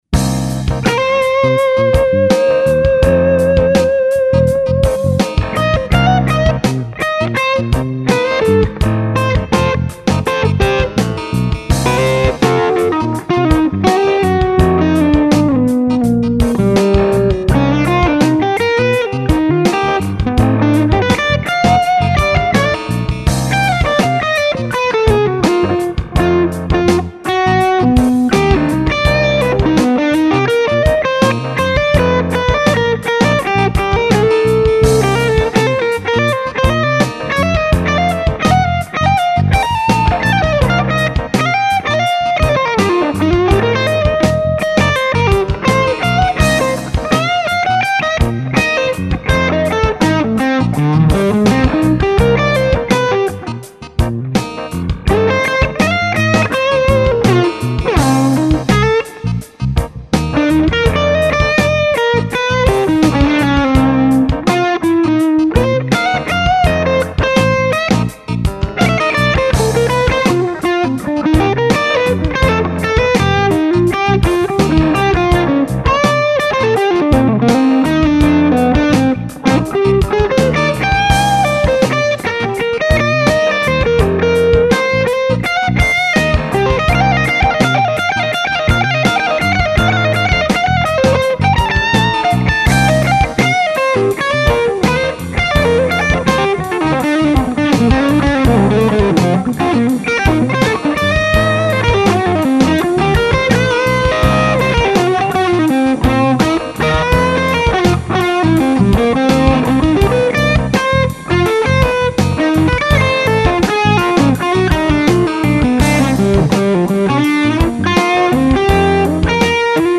(less R121 and different reverb)